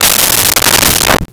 Desk Drawer Close 02
Desk Drawer Close 02.wav